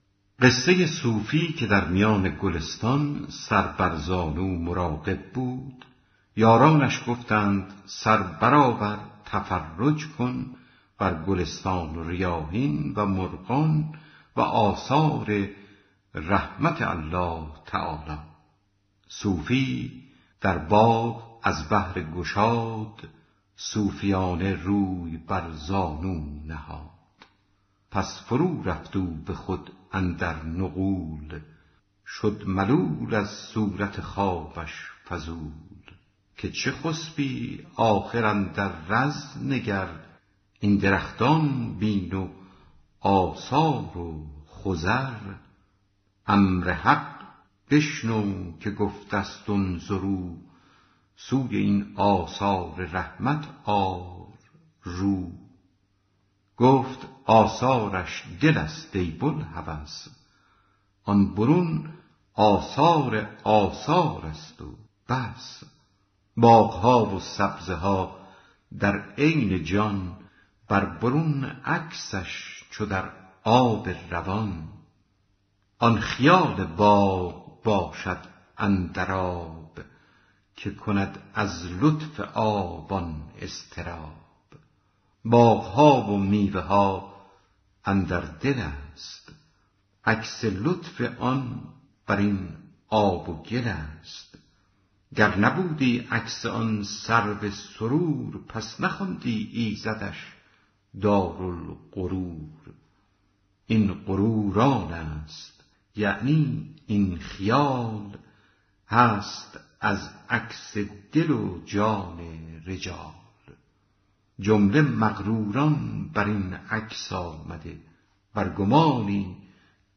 دکلمه قصه صوفی که در میان گلستان سر بر زانو مراقب بود